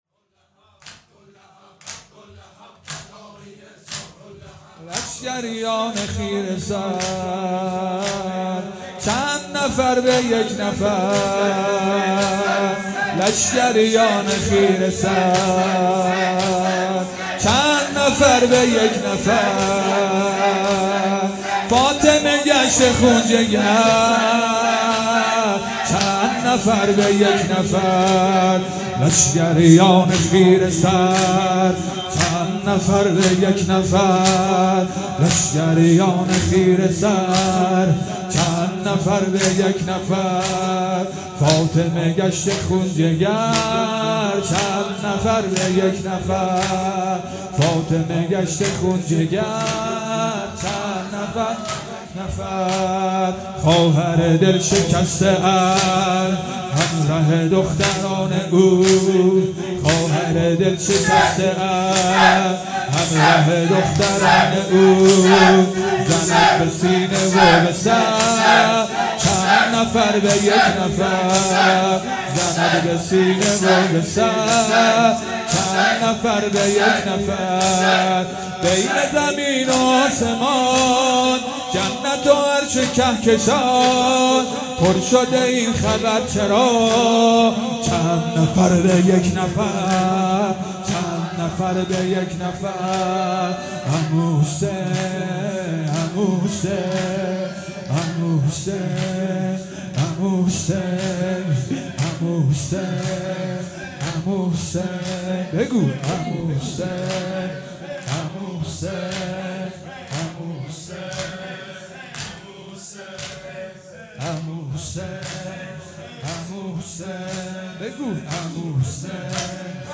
شب پنجم محرم الحرام 1441